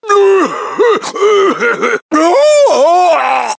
One of Donkey Kong's voice clips in Mario Kart 7